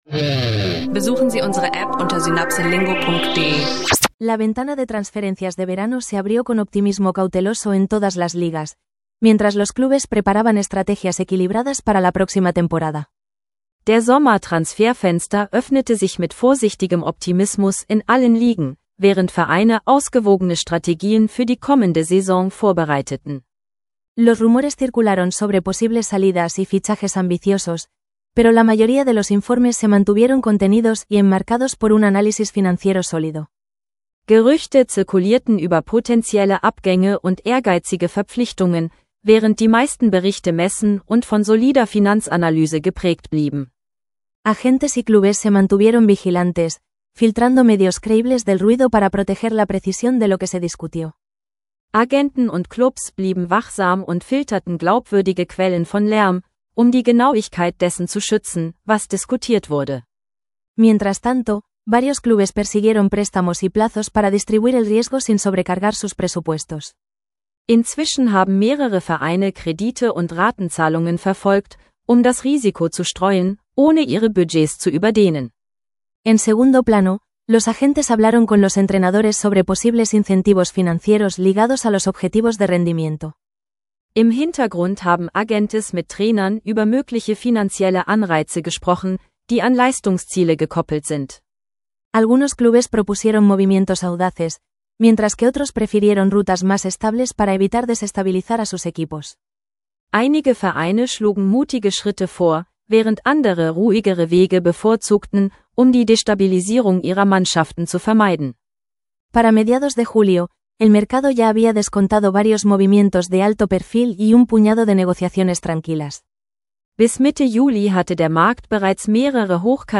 Spannende Einblicke in Sommer-Transferfenster und US Open – Spanisch lernen Podcast mit neutralem Ton und klaren Analysen